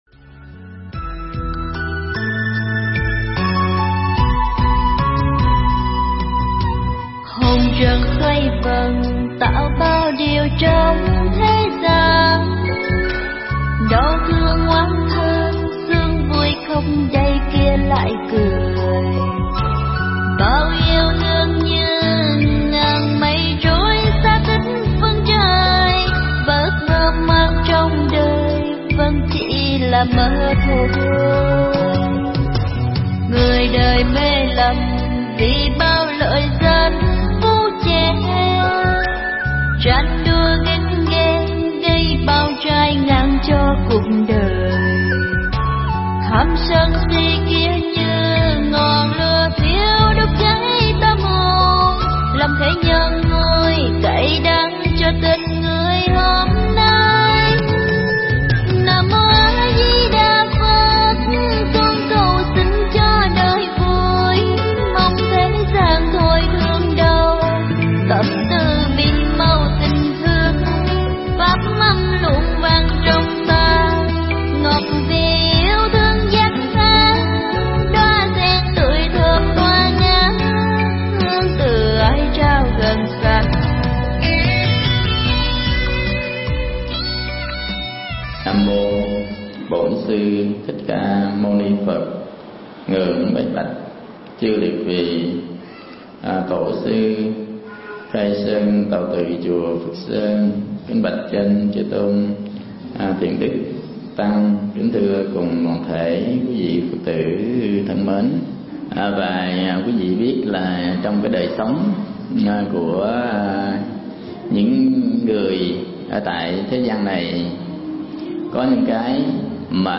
Mp3 Thuyết Giảng Bỏ Trước Khi Bị Bỏ
giảng tại Đạo tràng chùa Phước Sơn